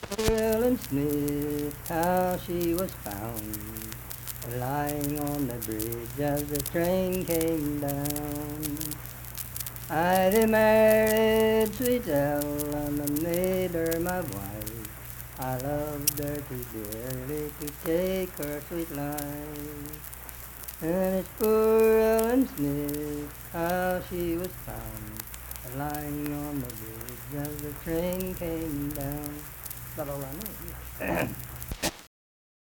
Unaccompanied vocal music
Voice (sung)
Lincoln County (W. Va.), Harts (W. Va.)